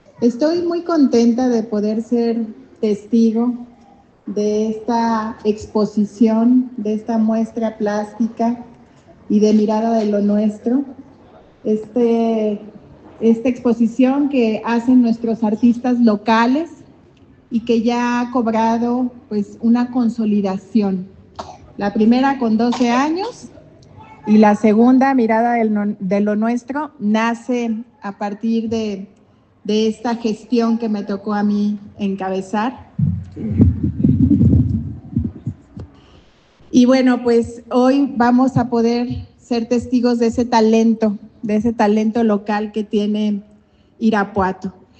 AudioBoletines
Lorena Alfaro García, presidenta municipal